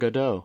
Waiting for Godot (/ˈɡɒd/ GOD-oh or /ɡəˈd/
En-us-Godot.oga.mp3